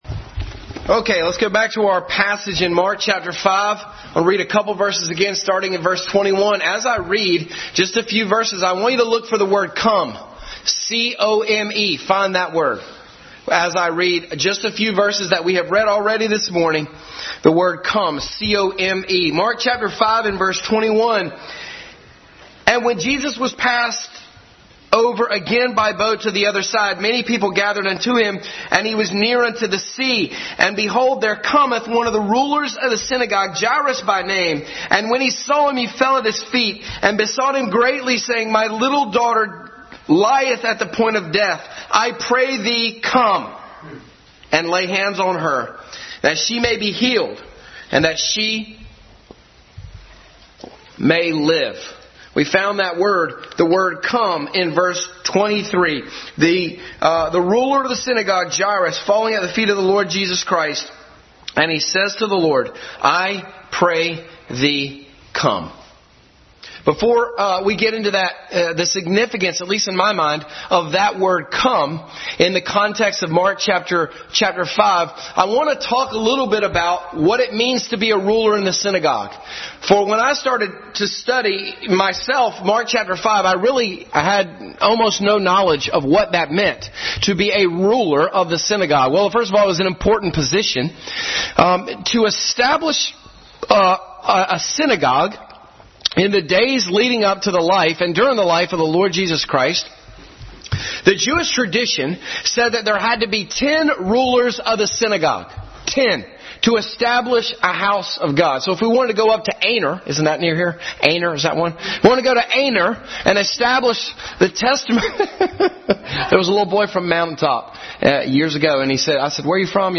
Passage: Mark 5:21-43, Malachi 4:2 Service Type: Family Bible Hour Family Bible Hour Message.